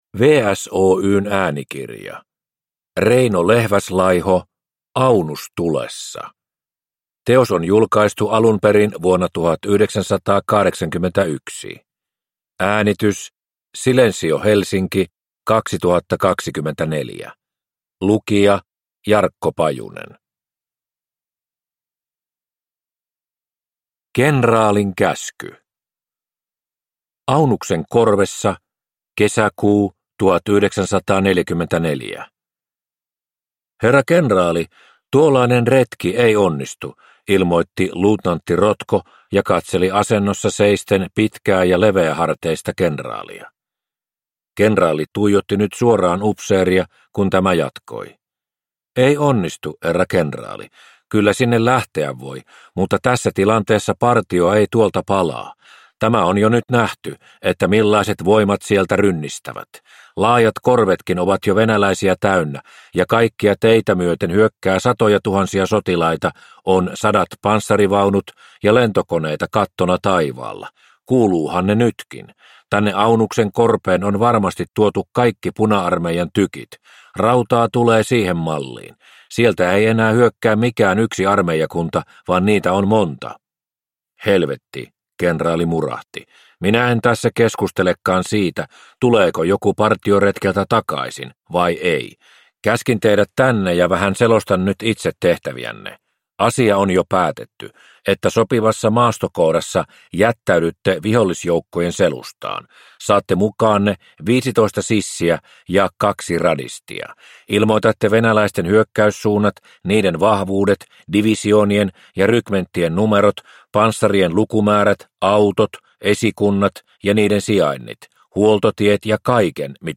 Aunus tulessa – Ljudbok